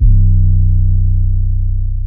B_808 - (M1 Slides).wav